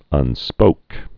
(ŭn-spōk)